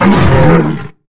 pain.wav